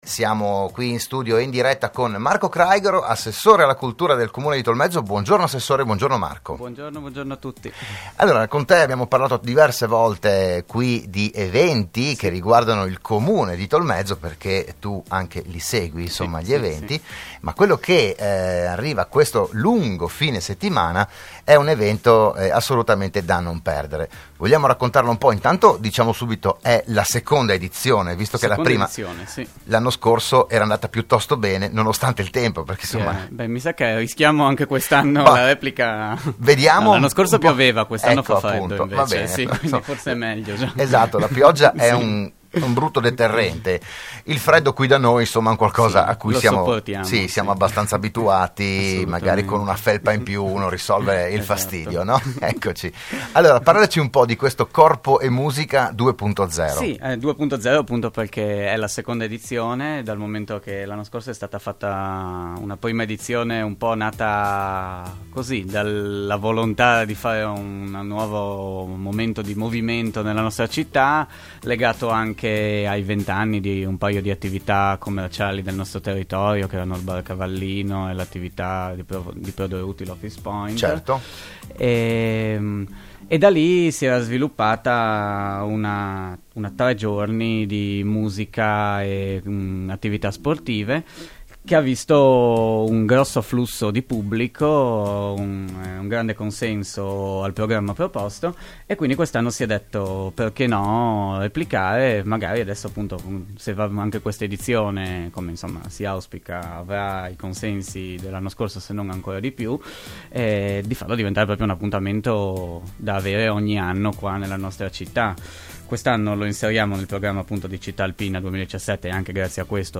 Dal 22 al 25 aprile la kermesse organizzata dal Gruppo Shangai in collaborazione con il Comune. Il PODCAST dell'intervento a RSN dell'assessore Marco Craighero